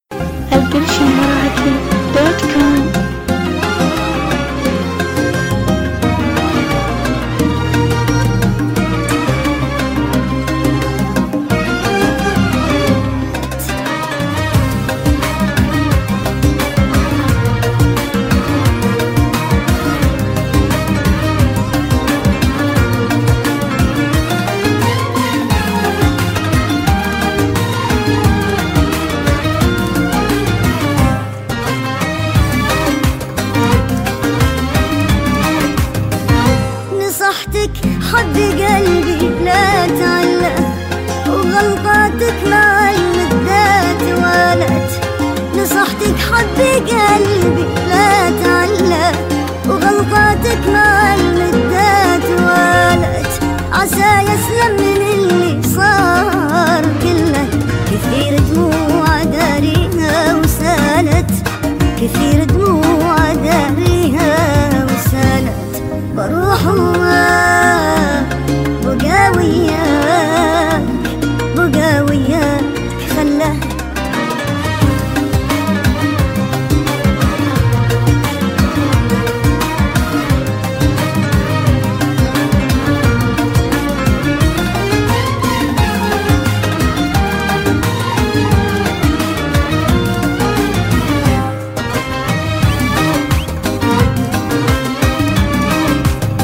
اغاني خليجيه